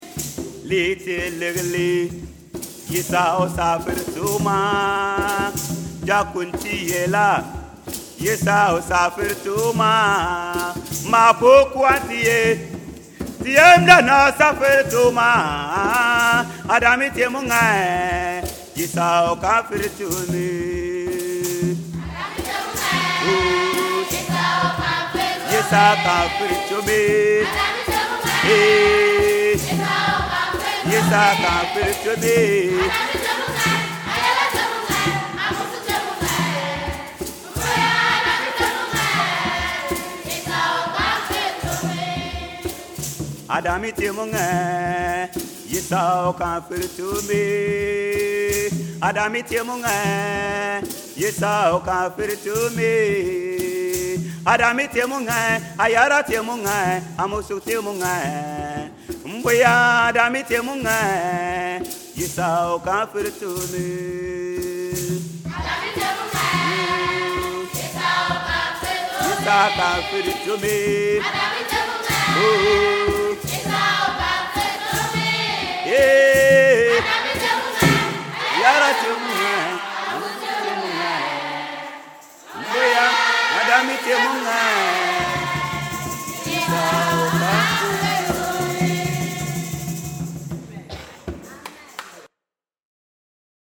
Yet the jubilant sounds of the choir and the encouraging messages and stories from the local pastors uncover a reality that stands in stark contrast to their surroundings — stories and songs of faith, hope, joy, and peace.
Several soloists took their turn, from young girls to adult men, leading the songs.
It was exhausting and hot, but the singers smiled and swayed to the hand drums.
bimba-choir-sample.mp3